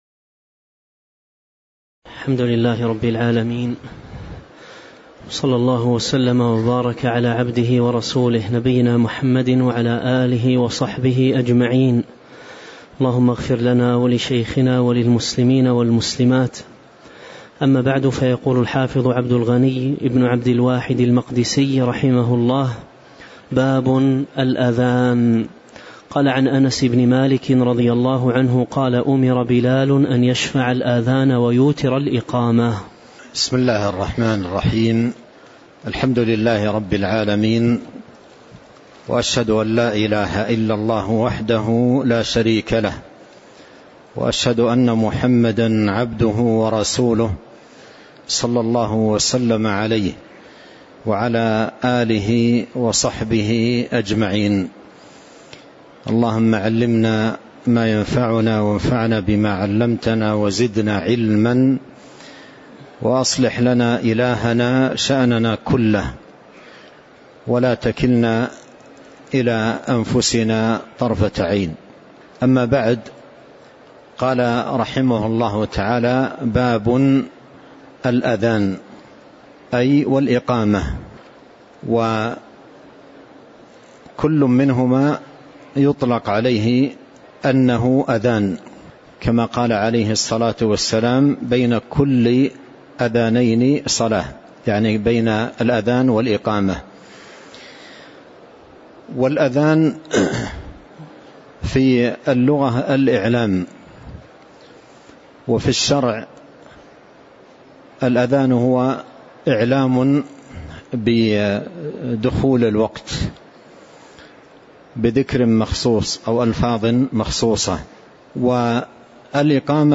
تاريخ النشر ٢٧ ربيع الأول ١٤٤٤ هـ المكان: المسجد النبوي الشيخ